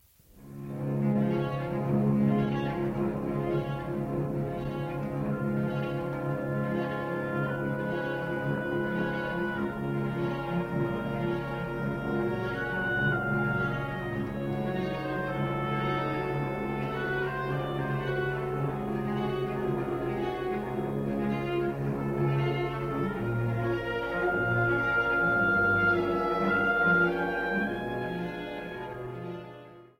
für großes Orchester und Orgel